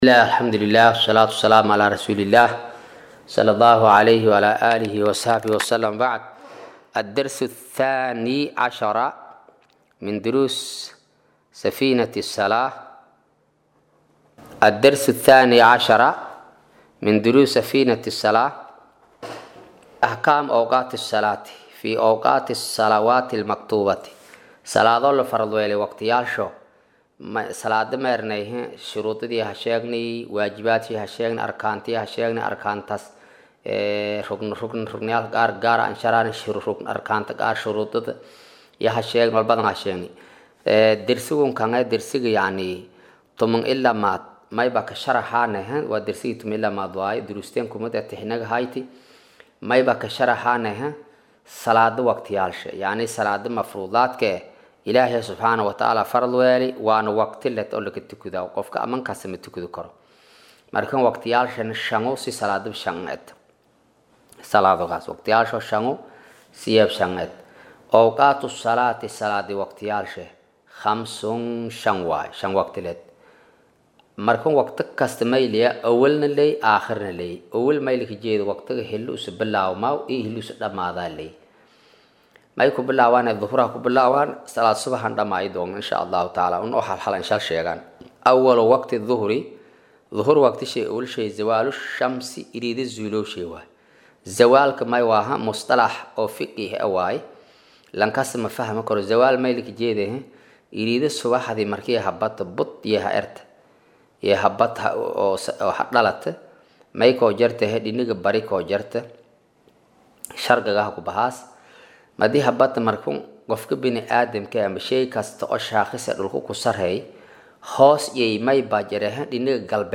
Maqal:- Casharka Safiinatu Najaa “Darsiga 12aad”